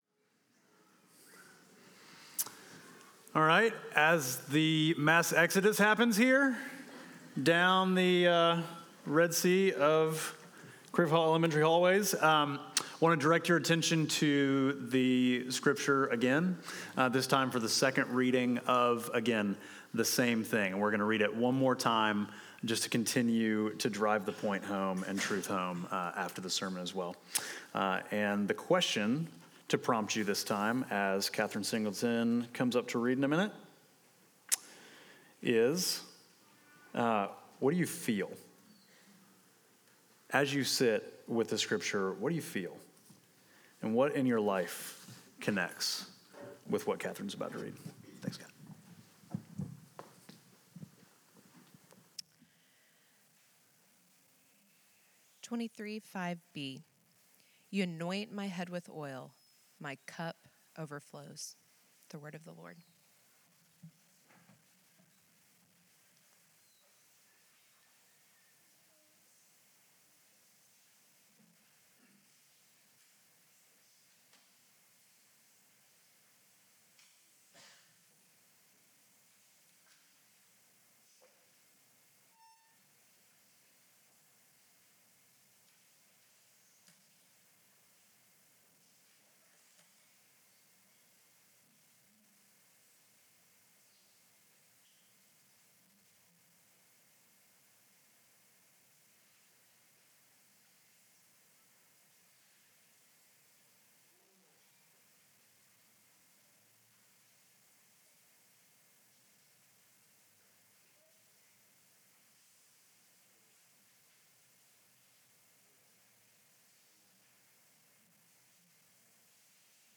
Midtown Fellowship Crieve Hall Sermons Confusing Kindness Jul 21 2024 | 00:39:01 1x 00:00 / 00:39:01 Subscribe Share Apple Podcasts Spotify Overcast RSS Feed Share Link Embed